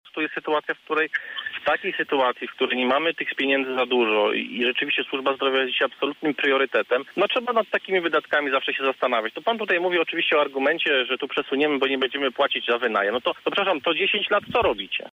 Do sprawy odniósł się również Grzegorz Maćkowiak z Prawa i Sprawiedliwości, który zauważył, że wydawanie takich pieniędzy w czasie pandemii jest całkowicie bezpodstawne: